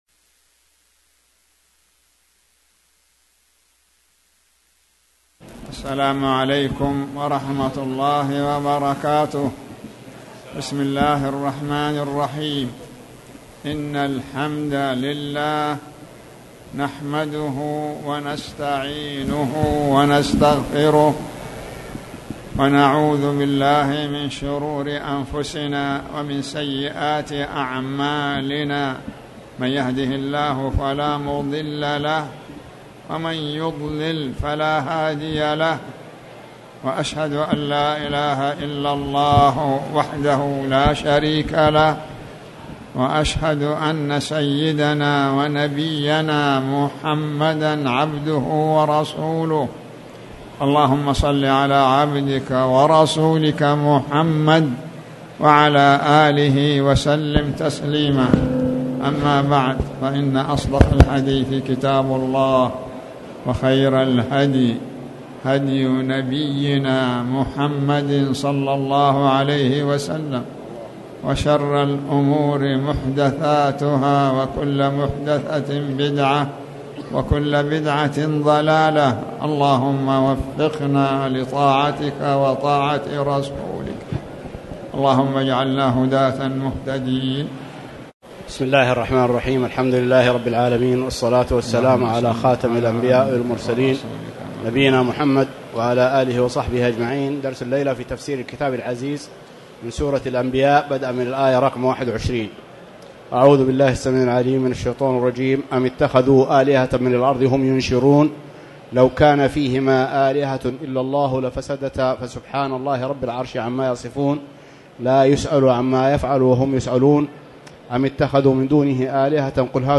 تاريخ النشر ١ ذو القعدة ١٤٣٨ هـ المكان: المسجد الحرام الشيخ